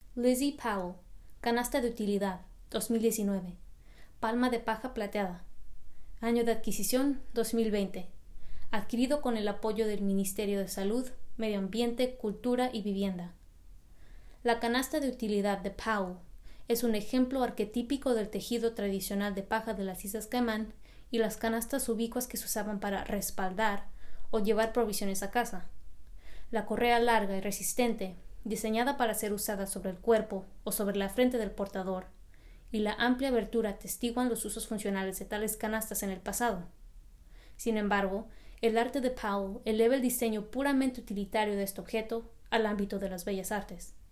Utility Basket Voiceover